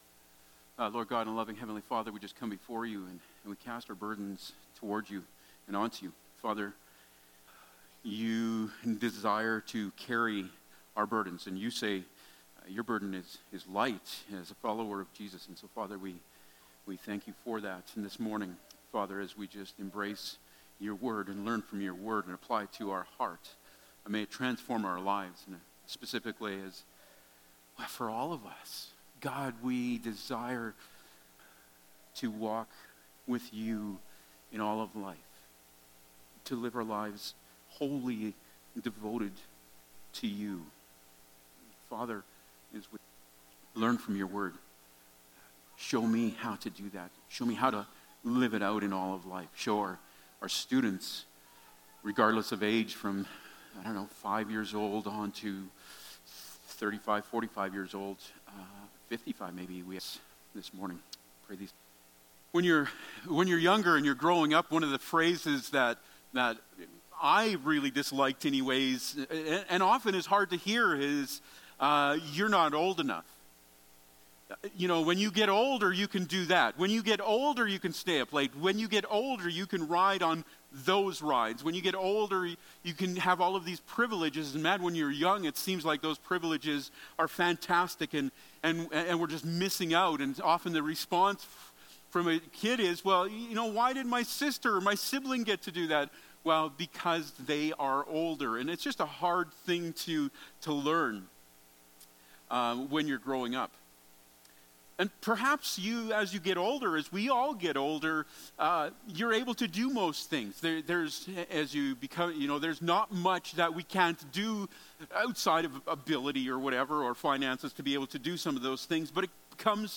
Passage: 2 Chronicles 34:1-7 Service Type: Sunday Morning